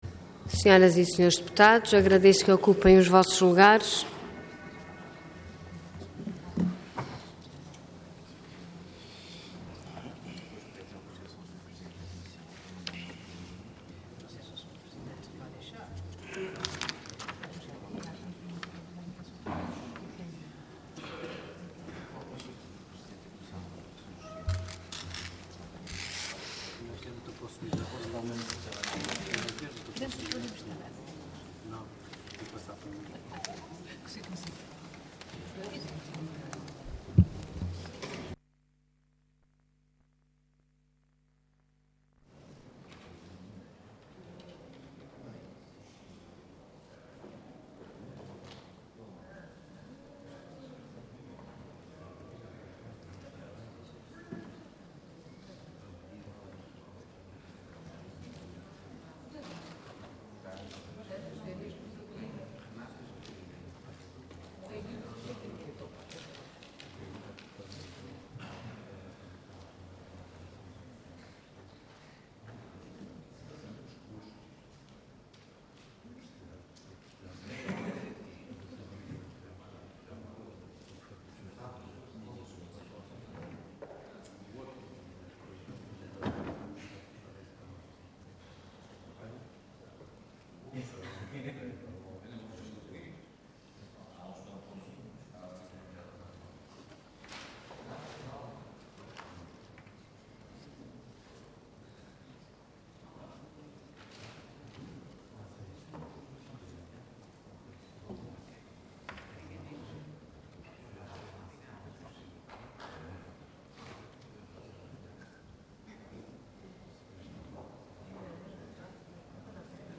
Intervenção Pedido de urgência seguido de debate Orador Artur Lima Cargo Deputado Entidade CDS-PP